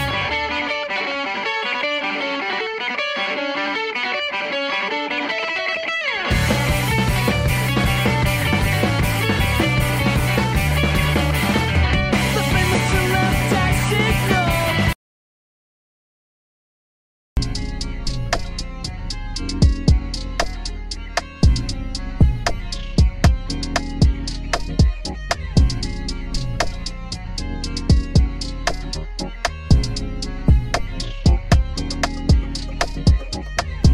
Good guitars